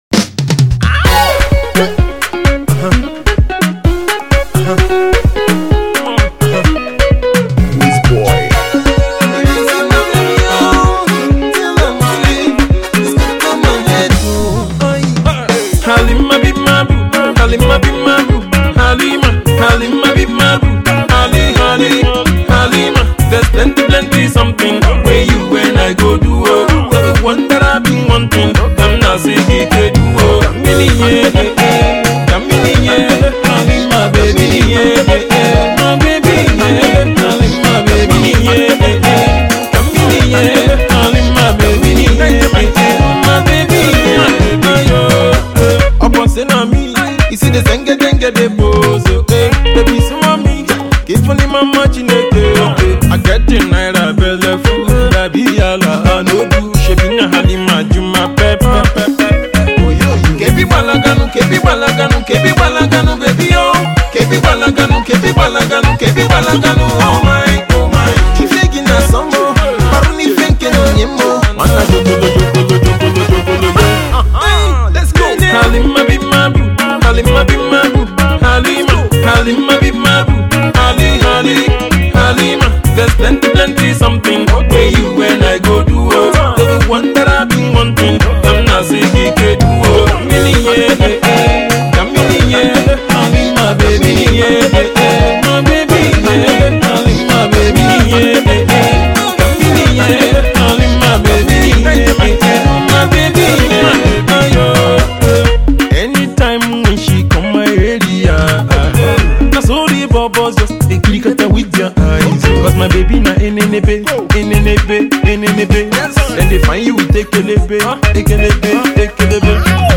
funky tune